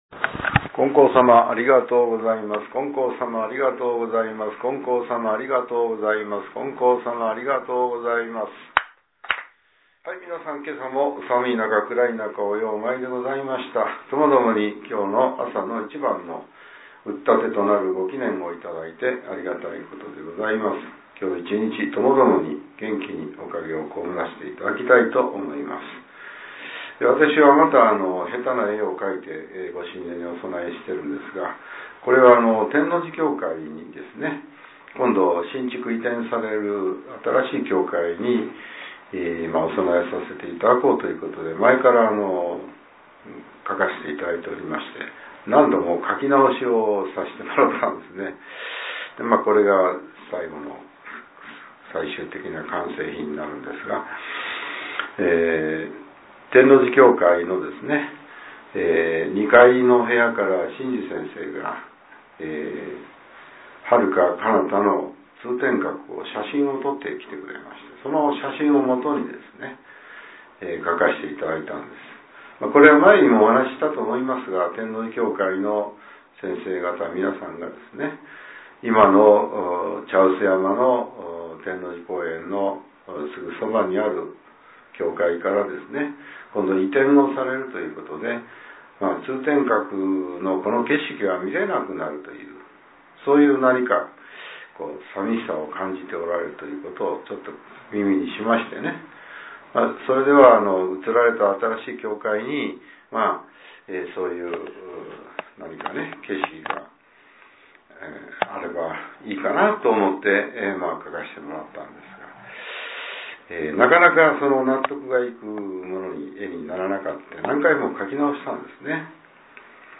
令和６年１２月１７日（朝）のお話が、音声ブログとして更新されています。